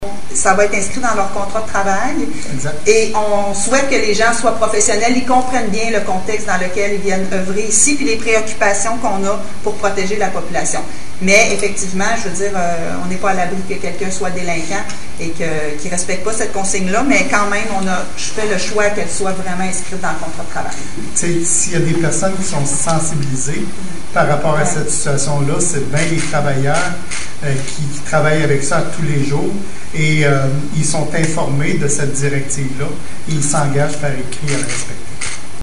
Les voici tour à tour, lors d’une conférence de presse diffusée sur Facebook: